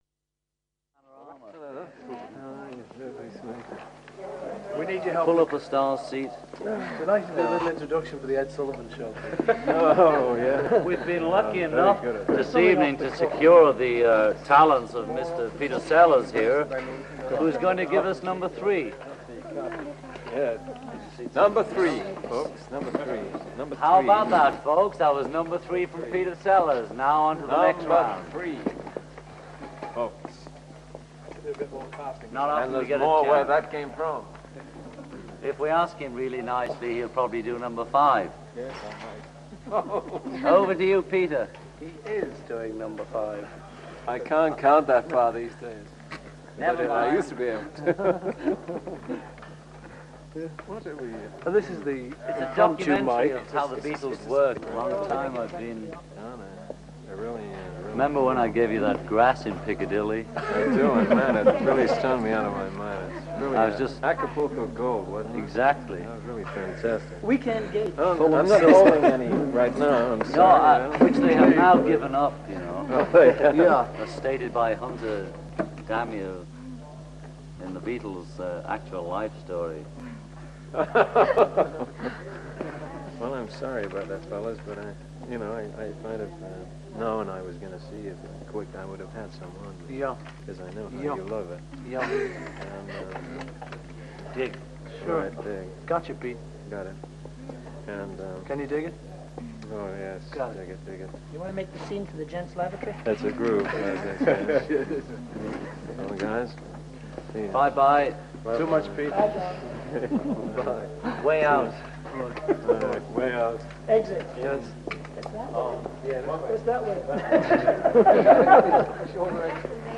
MP3 of Peter Sellers talking with the Beatles (sellersbeatles.mp3)
sellersbeatles.mp3